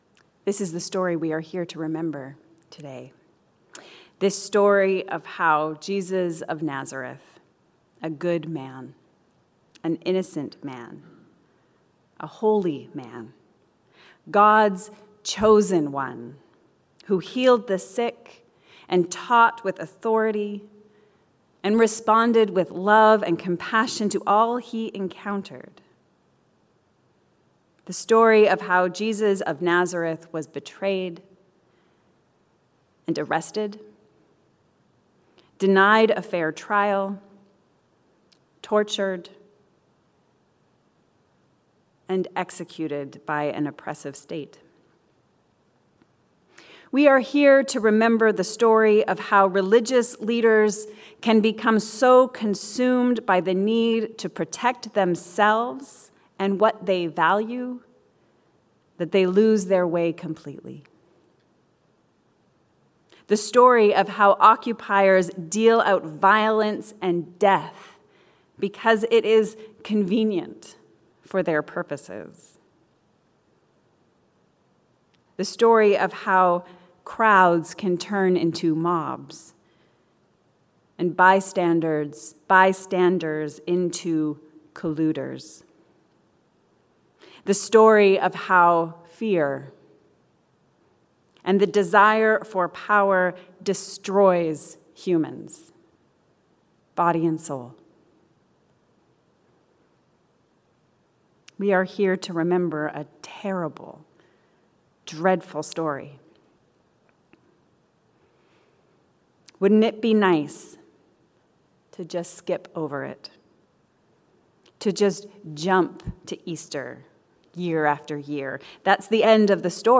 We are here to remember. A sermon for Good Friday